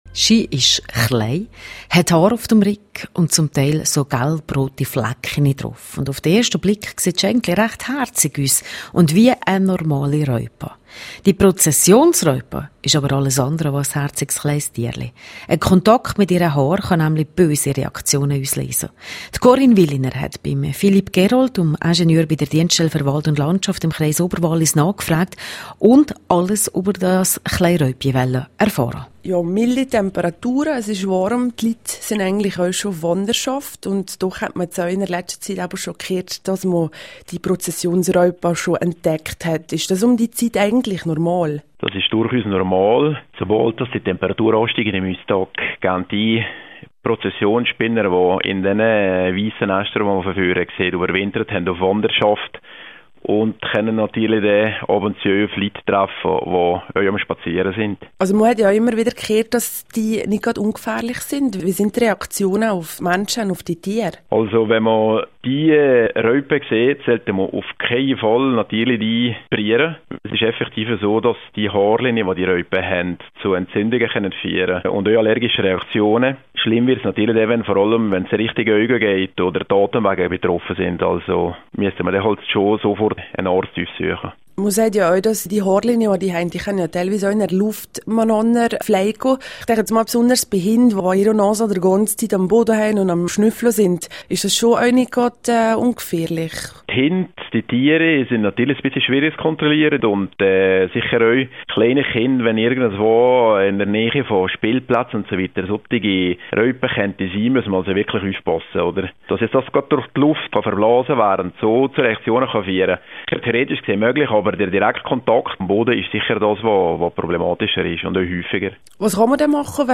Prozessionsraupen - nicht ganz ungefährlich für Mensch und Tier. Interview